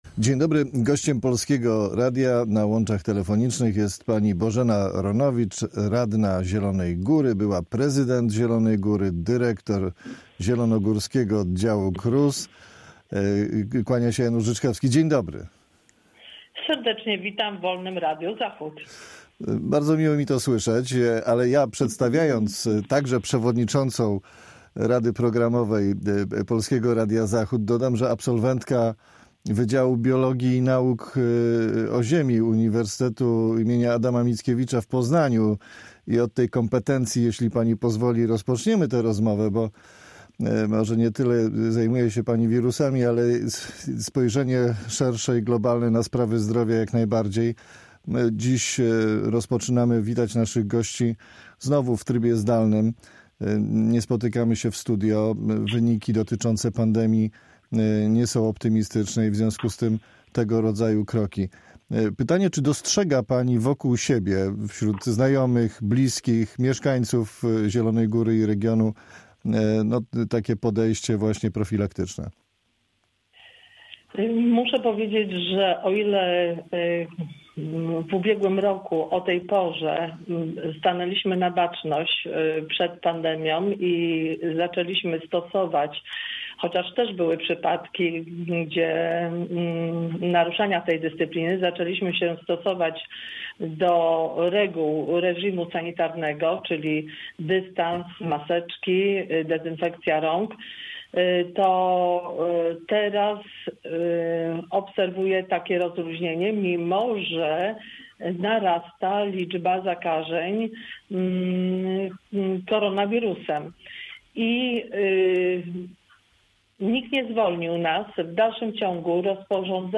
Z Bożeną Ronowicz, radną Zielonej Góry, Prawo i Sprawiedliwość rozmawia